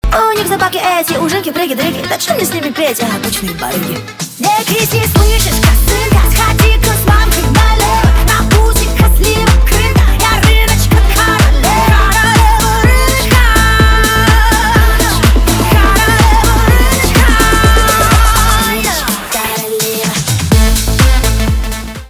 танцевальные
ремиксы
басы , качающие